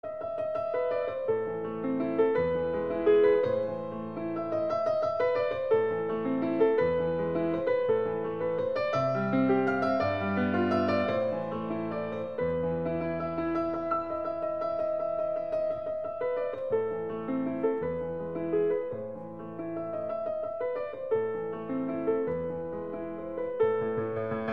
Categoria Classiche